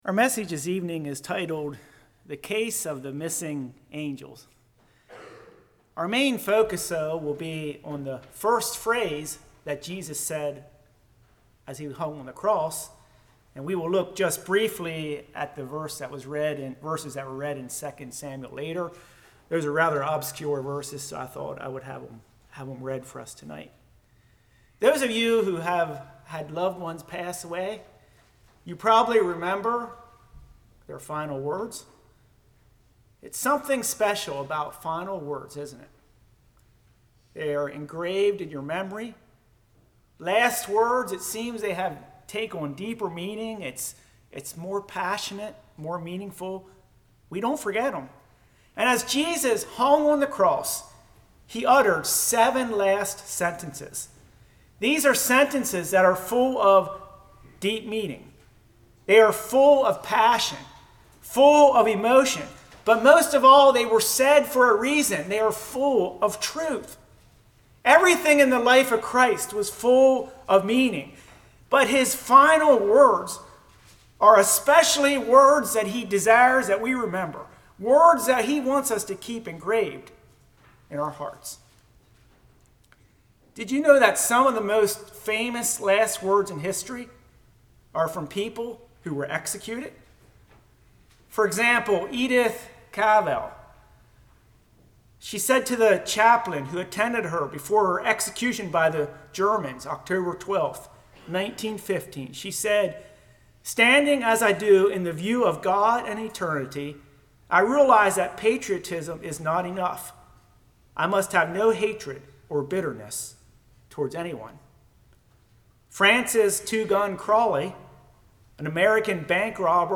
Service Type: Good Friday